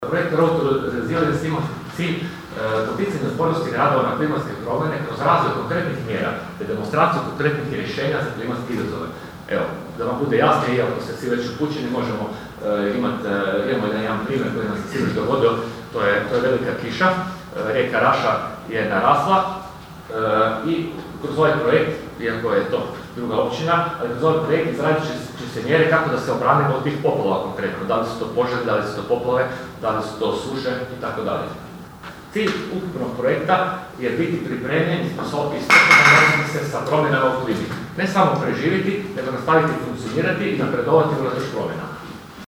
Grad Labin danas je domaćin stručnog seminara „Klimatska otpornost zajednice – lokalni pristupi i primjeri dobre prakse“, koji se održava u sklopu europskog projekta “Road 2 Resilience” (R2R).